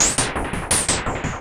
RI_RhythNoise_170-04.wav